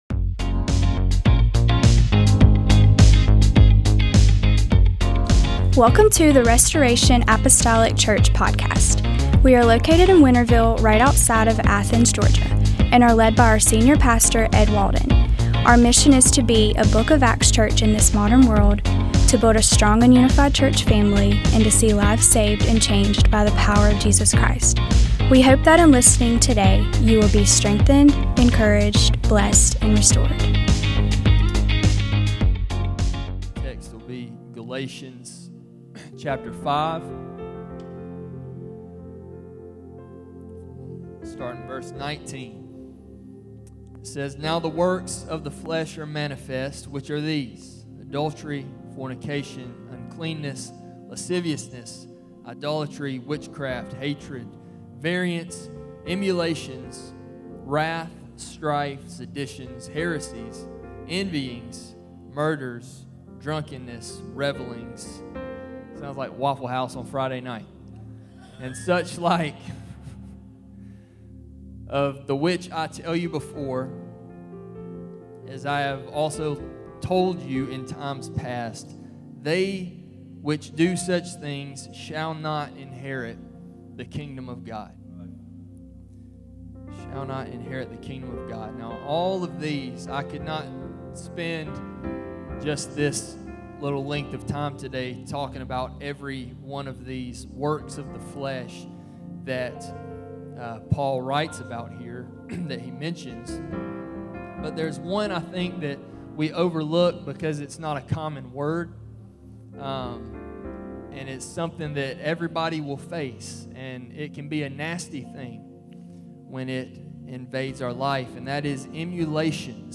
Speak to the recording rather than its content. Restoration Apostolic Church Emulations Aug 17 2025 | 00:35:27 1x 00:00 / 00:35:27 Subscribe Share Apple Podcasts Spotify Overcast RSS Feed Share Link Embed